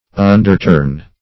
Search Result for " underturn" : The Collaborative International Dictionary of English v.0.48: Underturn \Un`der*turn\, v. t. To turn upside down; to subvert; to upset.